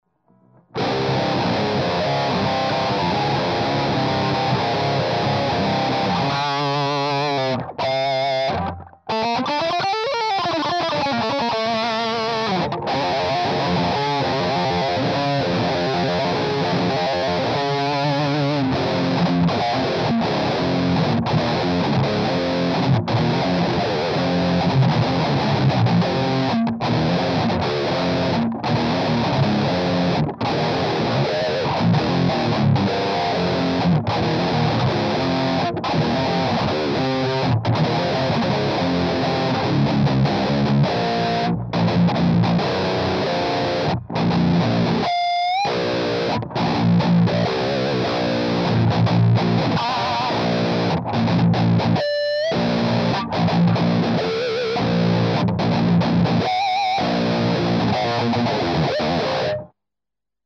примеров звука именно этого типа перегруза ("Metall") не меняя ручек настроек, а меняя лишь типы кабинетов.
(Гитара Ibanez RG-270, строй Drop C , струны 13-68).